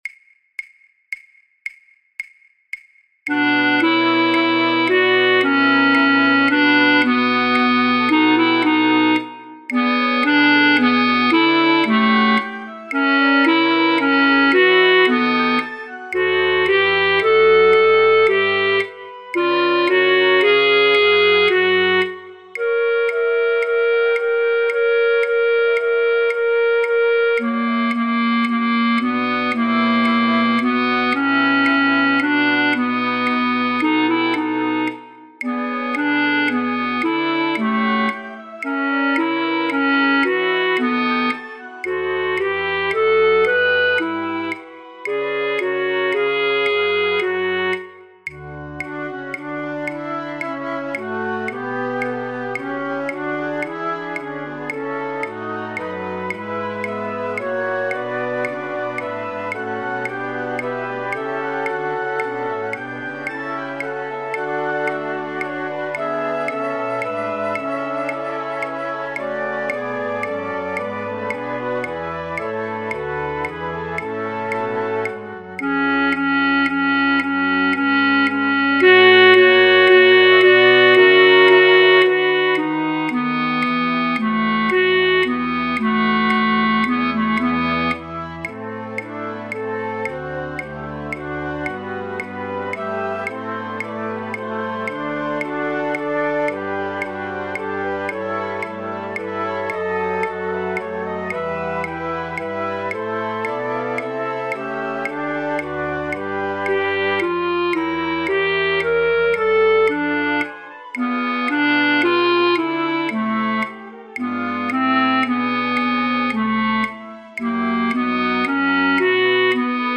Matthäus Passion – Oefentracks | Flutopia
alle partijen